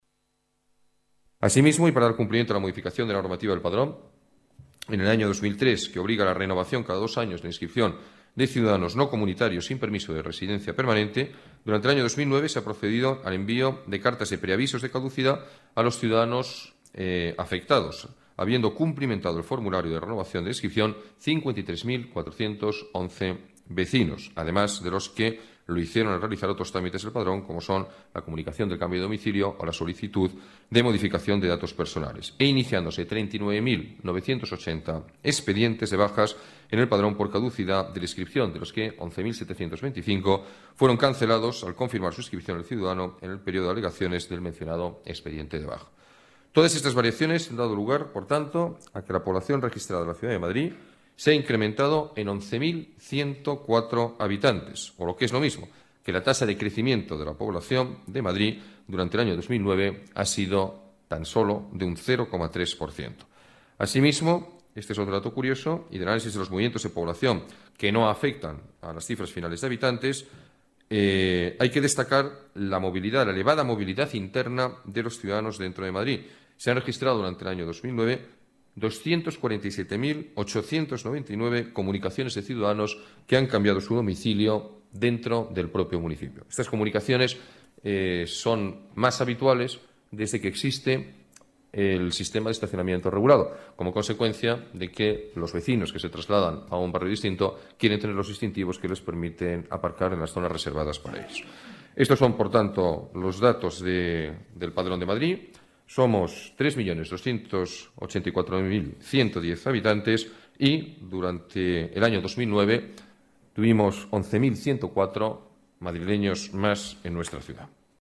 Nueva ventana:Declaraciones alcalde, Alberto Ruiz-Gallardón: datos actualización Padrón municipal